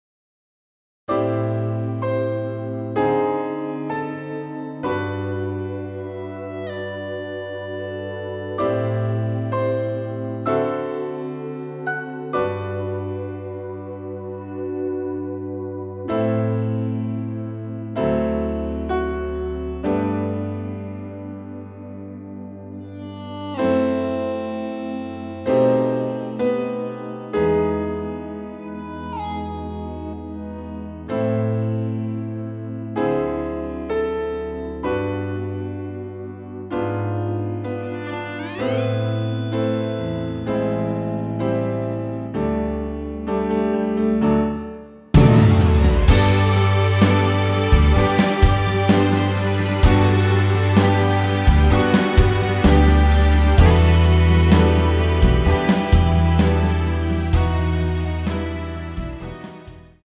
원키 MR입니다.
앞부분30초, 뒷부분30초씩 편집해서 올려 드리고 있습니다.
중간에 음이 끈어지고 다시 나오는 이유는